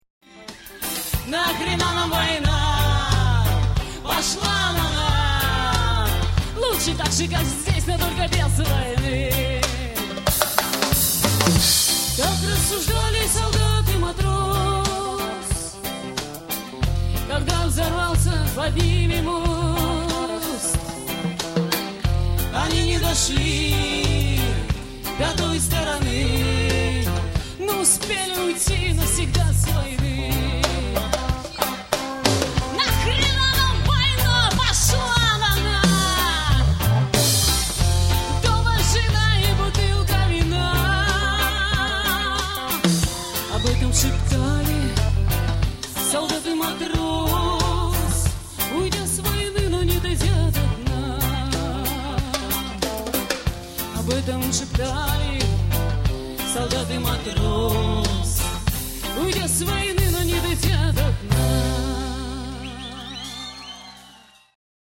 Концерт на Шаболовке (1995)
написанные в стиле регги
AUDIO, stereo